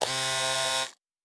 Servo Small 5_2.wav